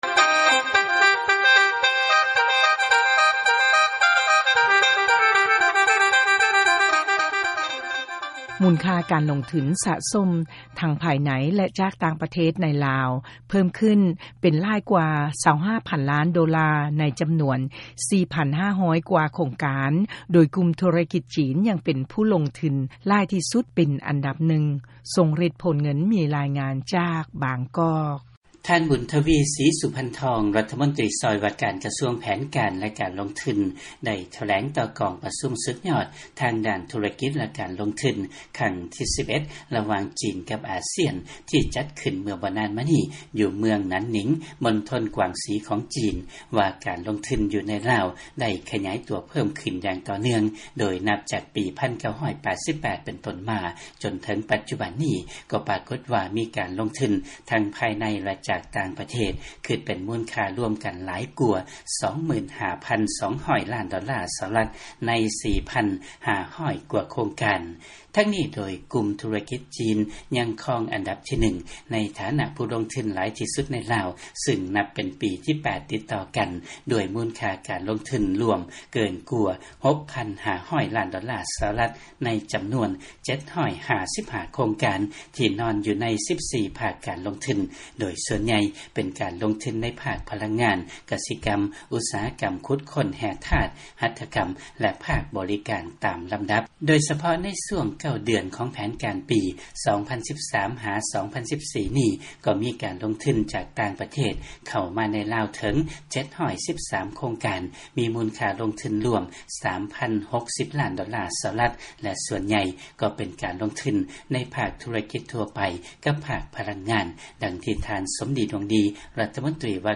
ຟັງລາຍງານ ມູນຄ່າການລົງທຶນ ທັງພາຍໃນ ແລະຈາກຕ່າງປະເທດ ໃນລາວ ເພີ້ມຂຶ້ນ ຫຼາຍກວ່າ 25 ຕື້ດອນລາ.